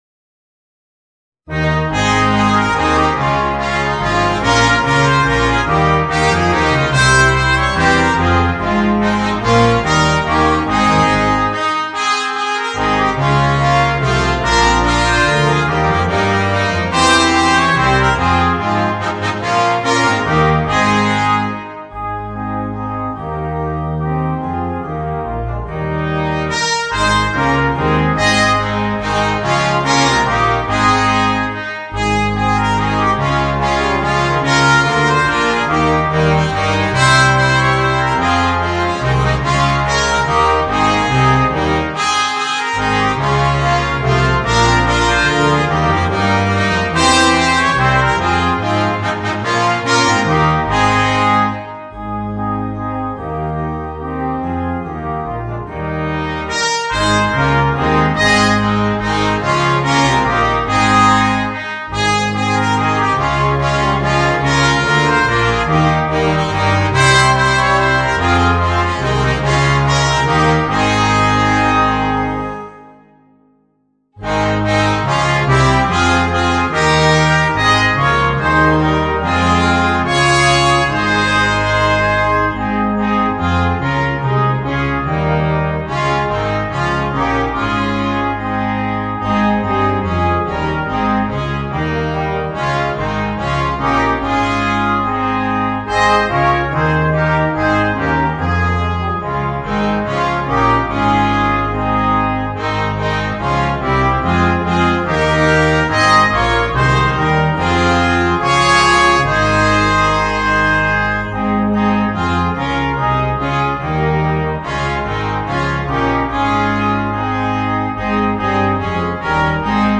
Gattung: für gemischtes Bläserquintett